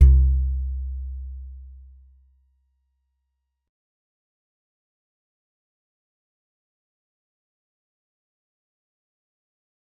G_Musicbox-C2-mf.wav